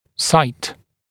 [saɪt][сайт]место, участок, местоположение, область
site.mp3